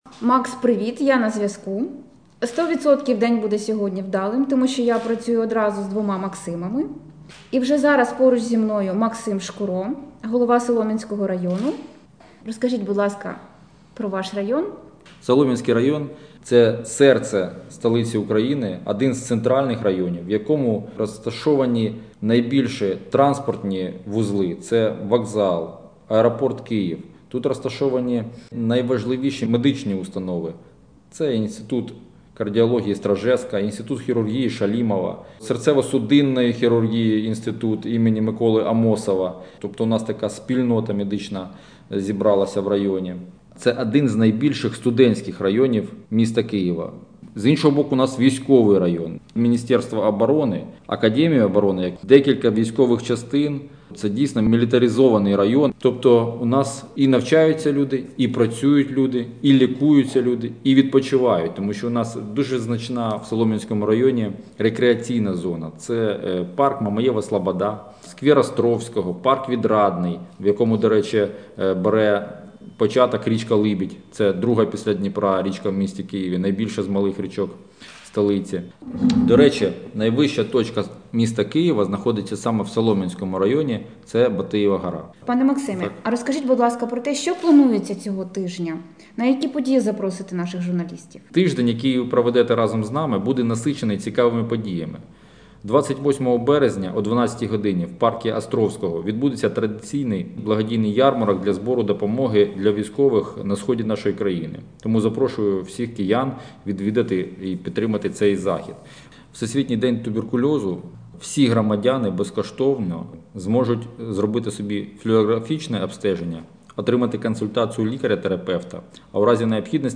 Сьогодні, у прямому ефірі на радіо «Київ 98 FM» голова Солом’янської райдержадміністрації Максим Шкуро розпочав проект «7 днів у районі».
Максим Шкуро розповів про Солом’янський район на радіо «Київ 98 FM»